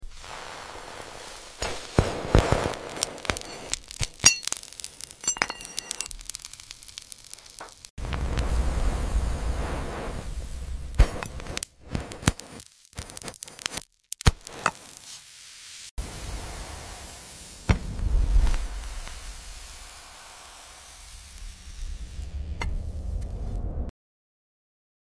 Visual ASMR